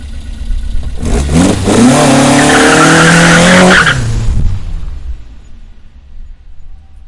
Громкий рев старта машины с пробуксовкой сорвался с места